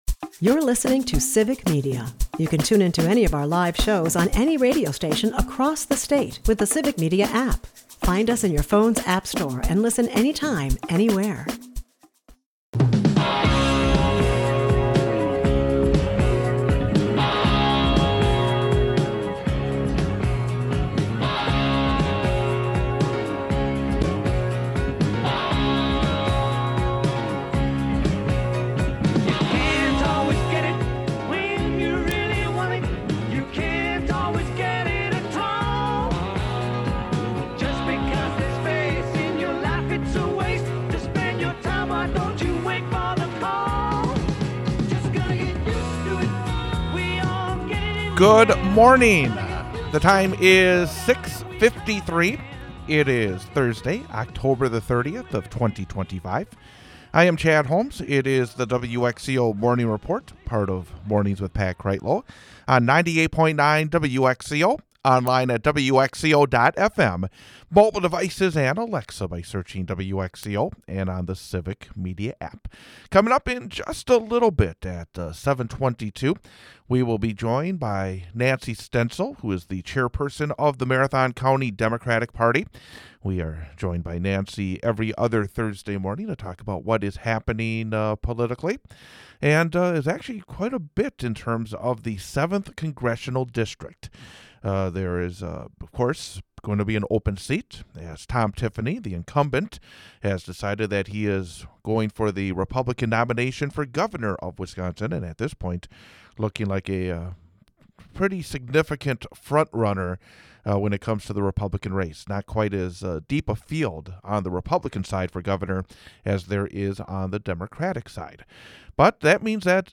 Political talk